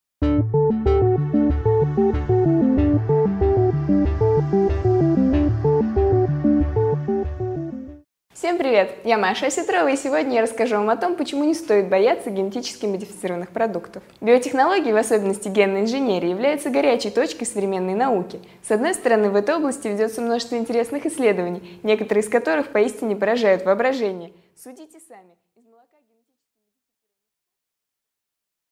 Аудиокнига 5 минут О пользе ГМО | Библиотека аудиокниг